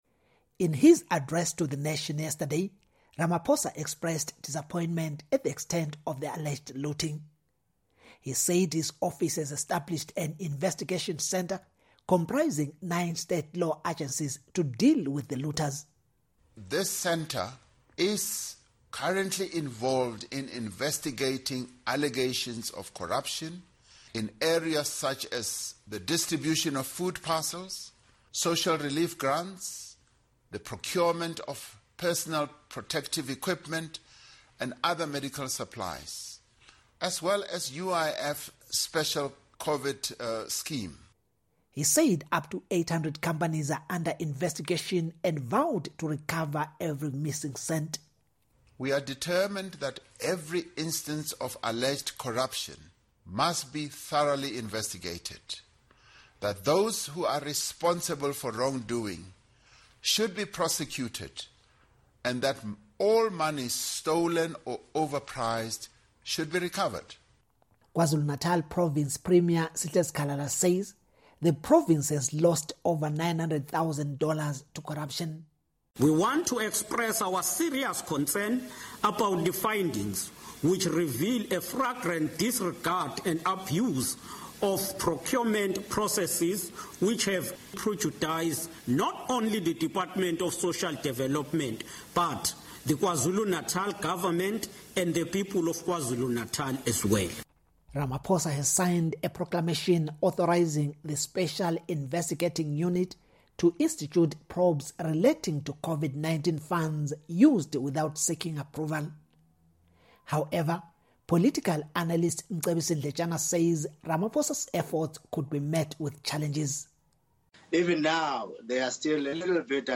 South Africa’s President Cyril Ramaphosa ordered an investigation into hundreds of companies suspected of looting COVID-19 funds. For Africa News Tonight, reporter